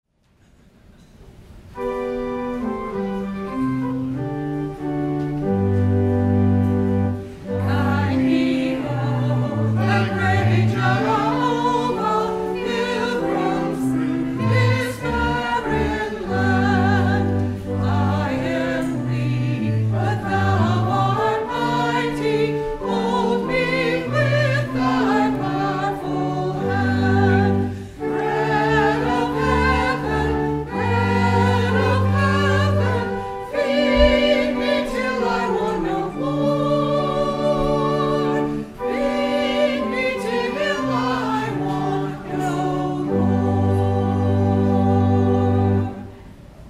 Performed by the Algiers UMC Choir